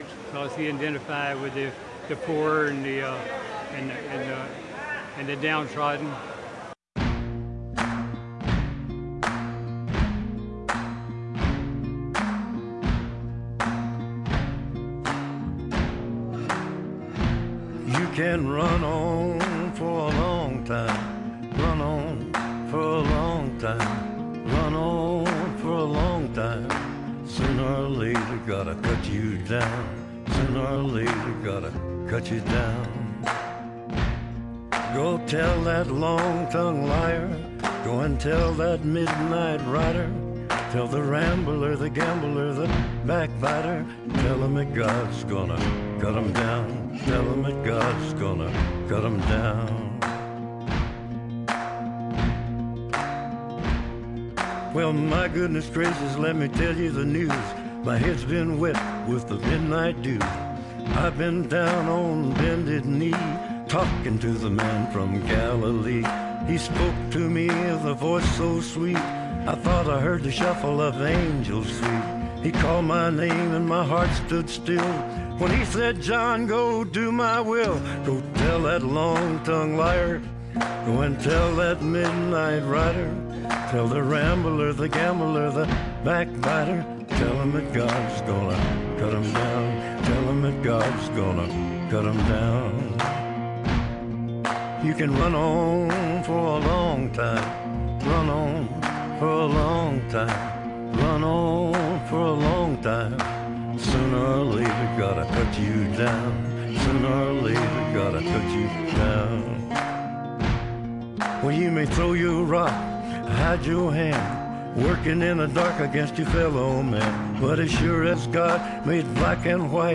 Если будут смельчаки, попробуем звонки в прямой эфир !